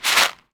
R - Foley 245.wav